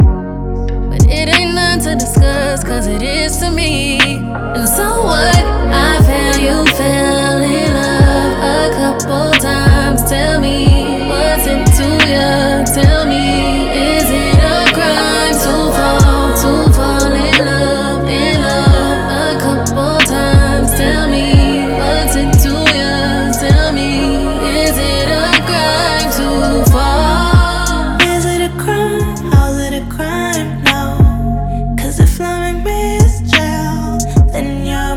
Плавные вокальные партии и чувственный бит
Глубокий соул-вокал и плотные хоровые партии
Жанр: R&B / Соул